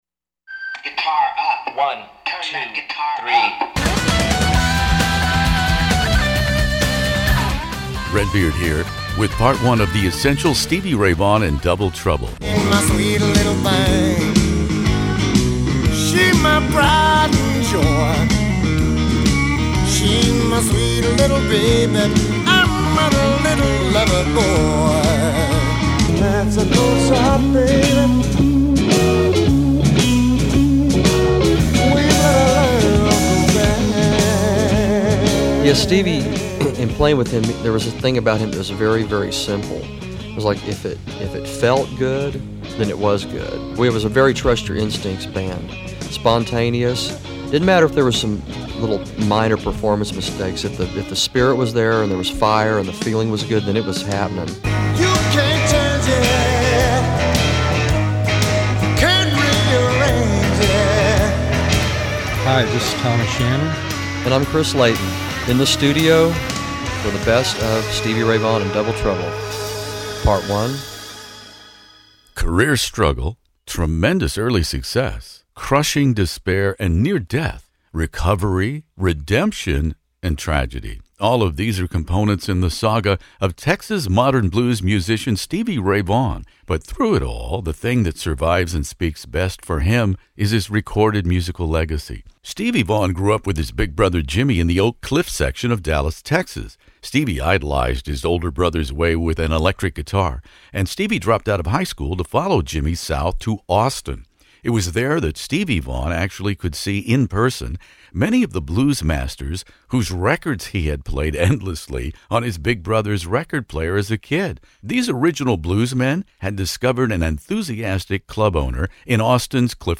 Double Trouble’s Chris Layton and Tommy Shannon are joined by blues legend Buddy Guy and my rare archival 1984 interview with the late Stevie Ray Vaughan here In the Studio for Stevie Ray Vaughan & Double Trouble’s Couldn’t Stand the Weather.